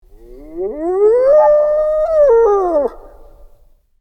Sound Effects
Sound Of Hyenas